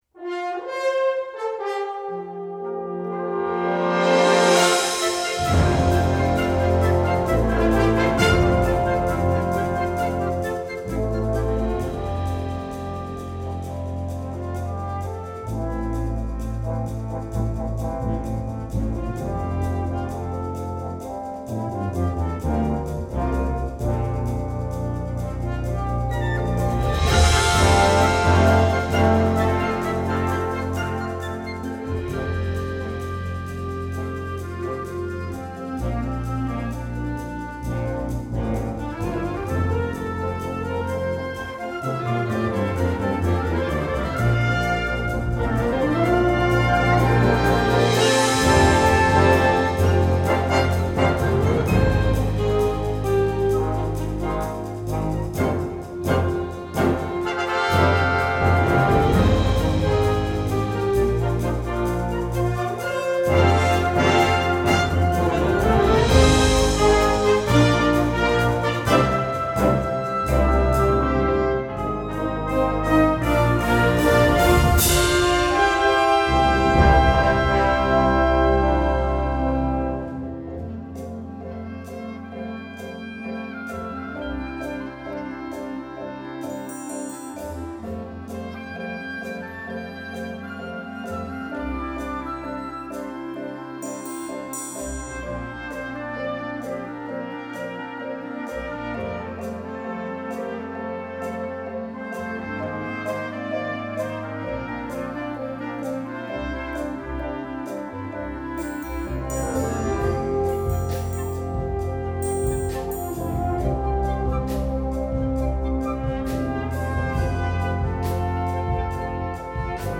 Gattung: Konzertwerk für Blasorchester
Besetzung: Blasorchester
im modernen, sinfonischen Stil